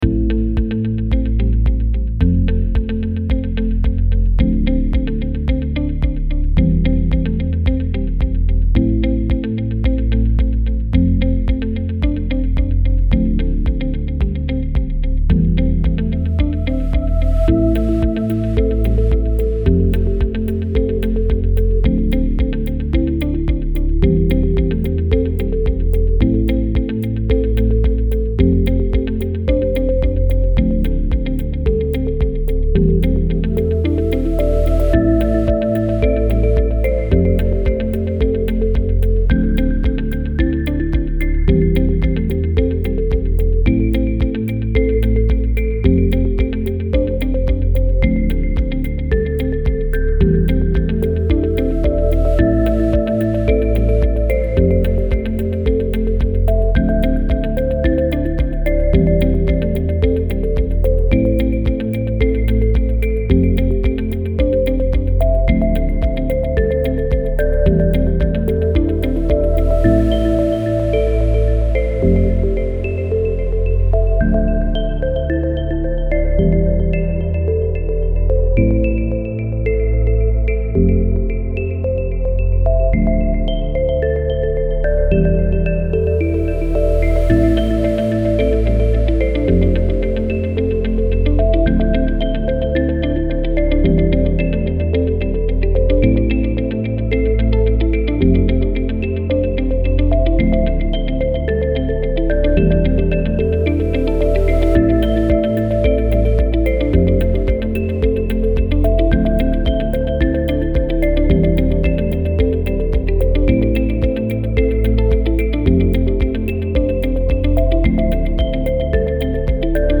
optimistic-minimalistic-ambient-corporate-203081.mp3